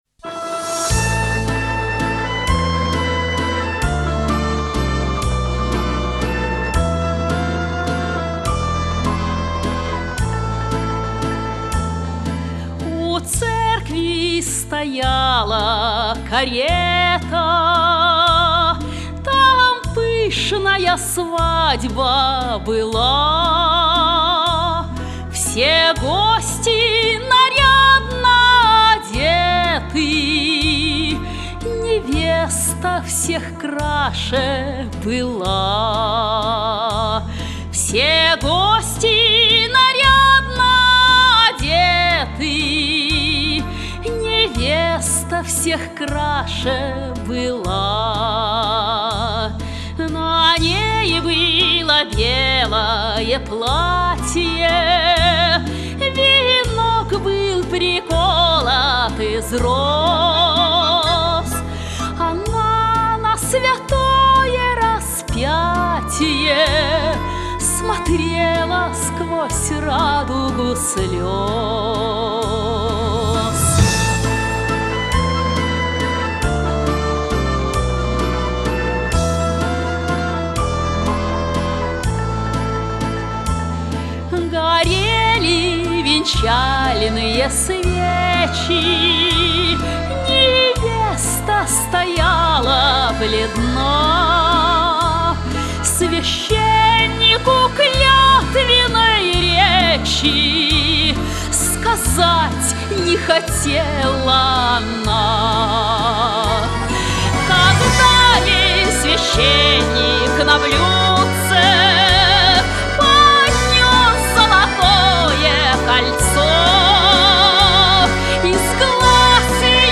Русские народные песни
застольные песни